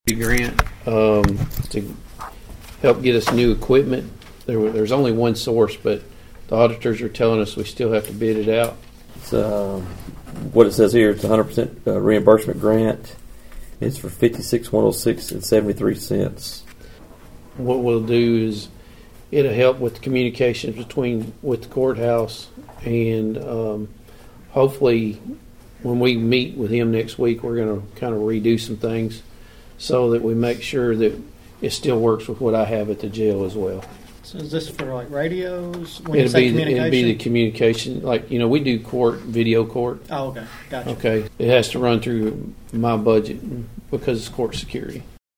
Obion County Sheriff Karl Jackson addressed the Budget Committee on Tuesday.